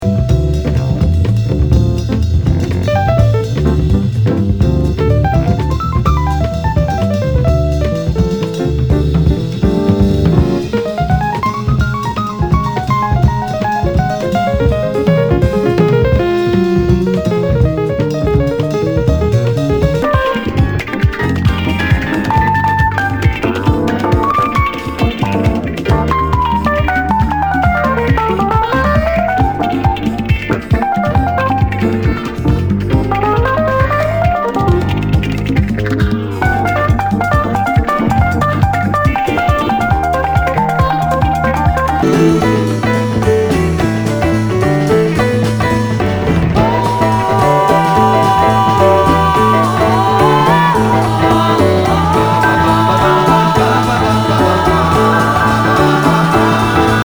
Nu- Jazz/BREAK BEATS
ナイス！ジャズ・ファンク！